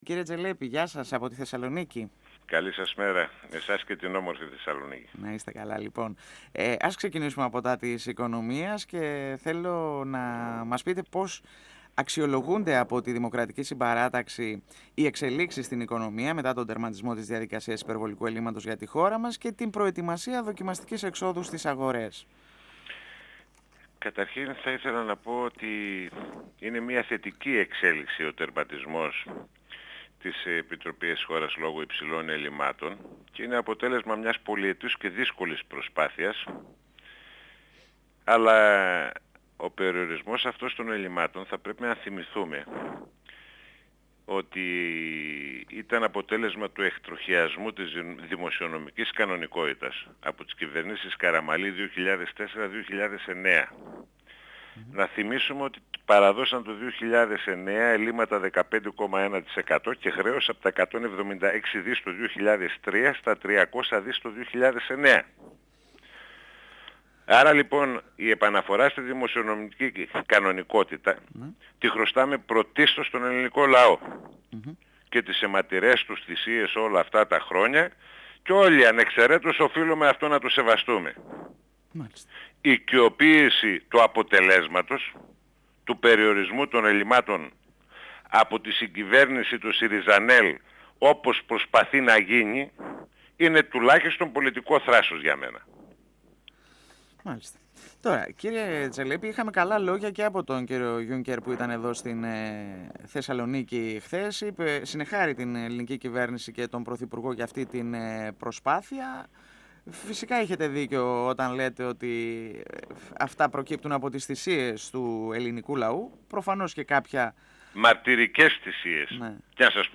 14Ιολ2017 – Ο βουλευτής Σερρών της Δημοκρατικής Συμπαράταξης Μιχάλης Τζελέπης στον 102 fm της ΕΡΤ3